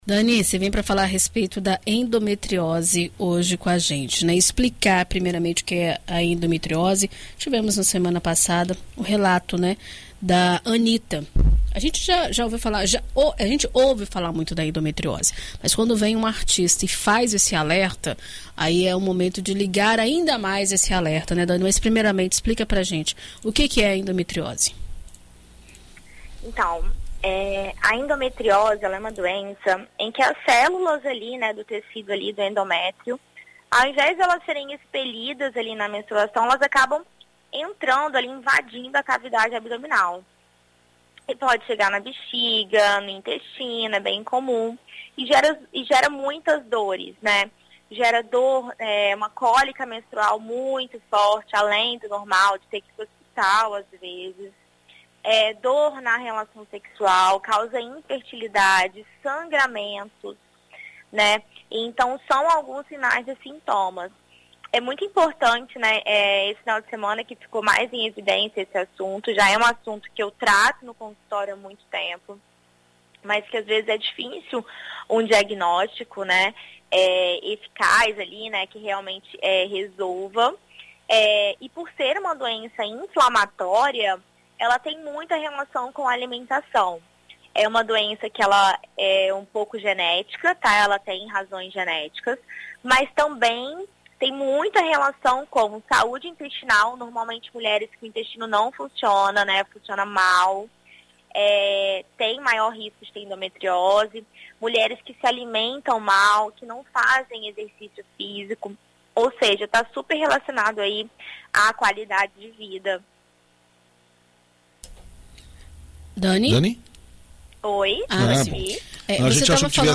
Na coluna Viver Bem desta quarta-feira (13), na BandNews FM Espírito Santo,